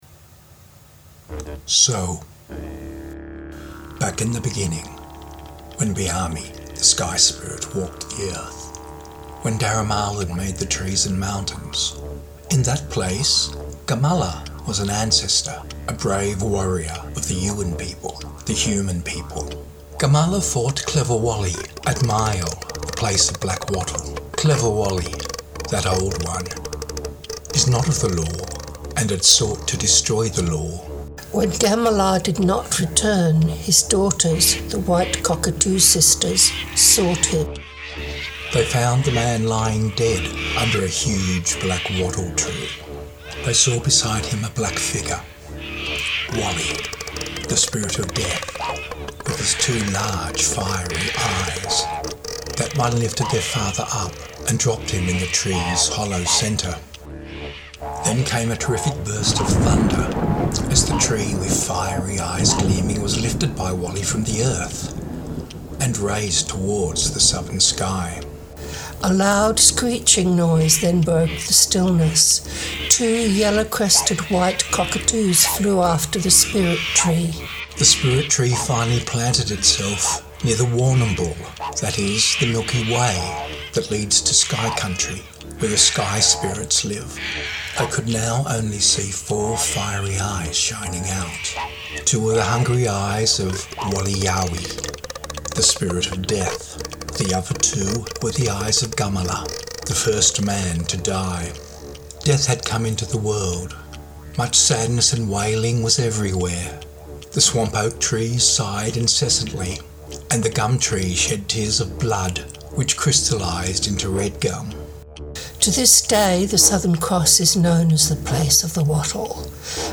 southern-cross-myth2.mp3